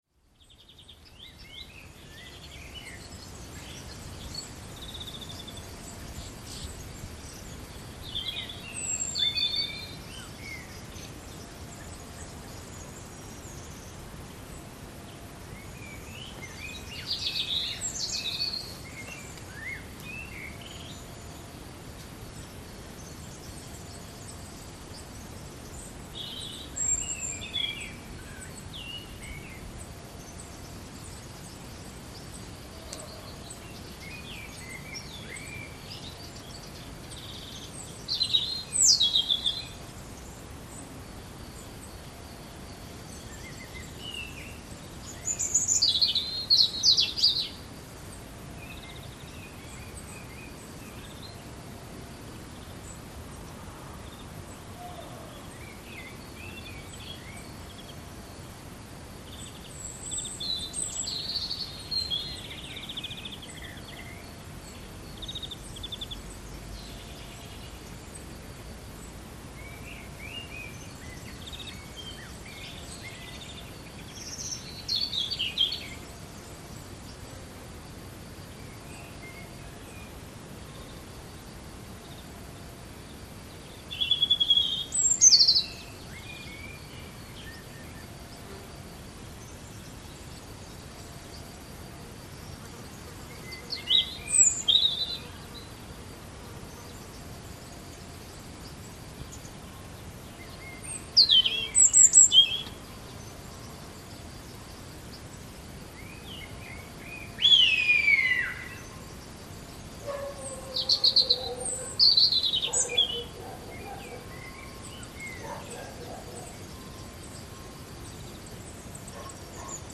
Portugese Daytime soundscape - Quinta das Abelhas Central Portugal - June 2008